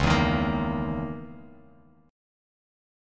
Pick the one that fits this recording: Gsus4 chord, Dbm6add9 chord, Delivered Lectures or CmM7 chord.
Dbm6add9 chord